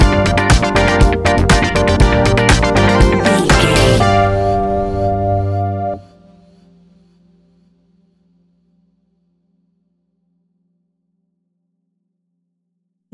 Ionian/Major
F♯
chilled
laid back
Lounge
sparse
new age
chilled electronica
ambient
atmospheric
morphing